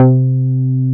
R MOOG C4MP.wav